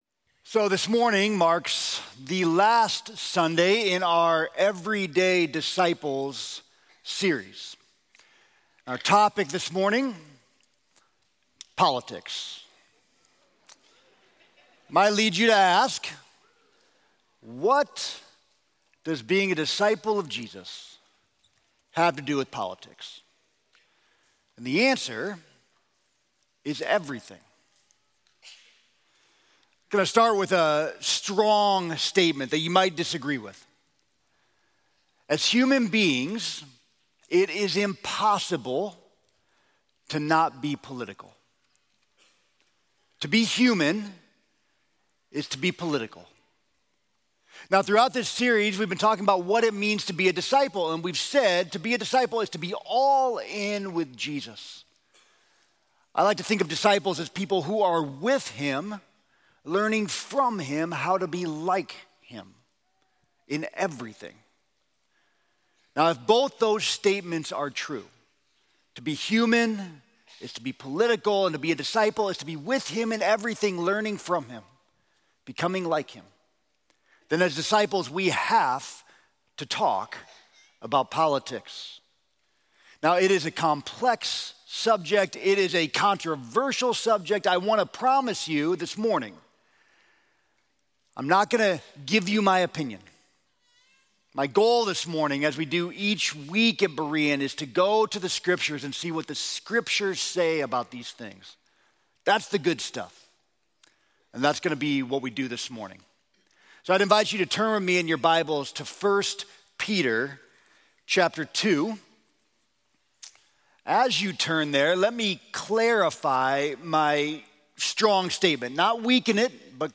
Sermon: Politics